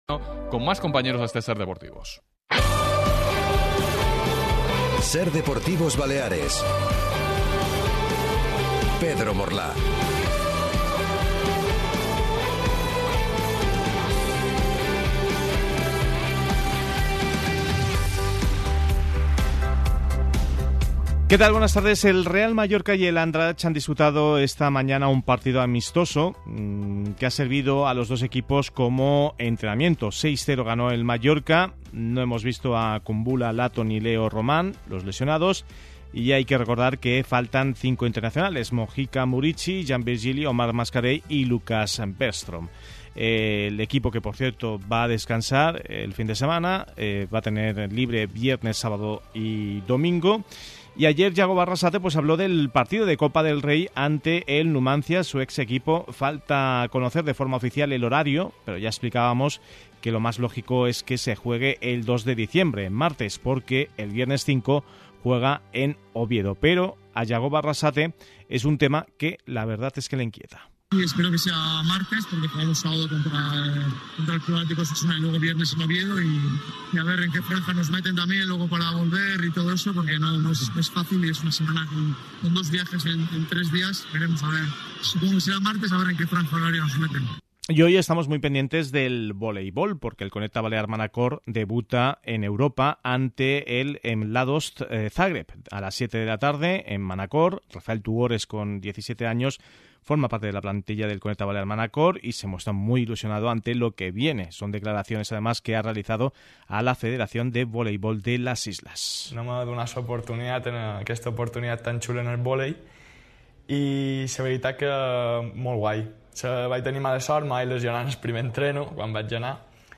Escucha la entrevista a partir del minuto 30 del Podcast.